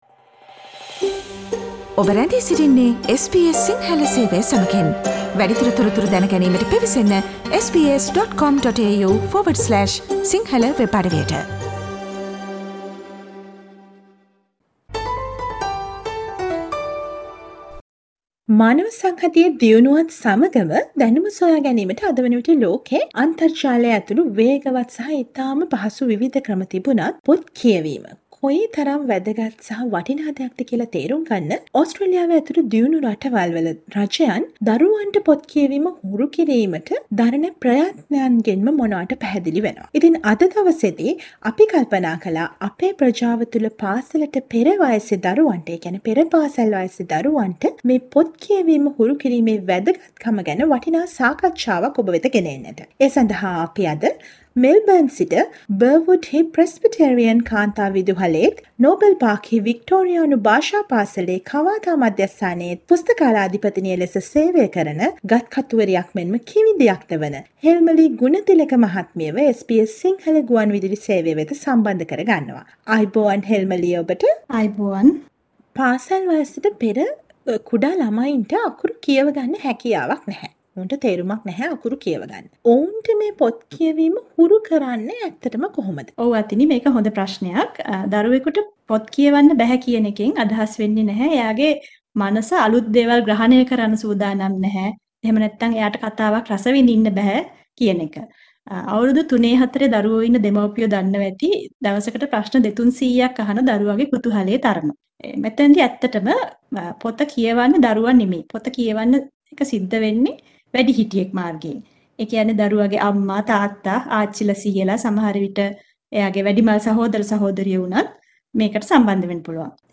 SBS සිංහල ගුවන්විදුලි සේවය සිදු කළ සාකච්ඡාව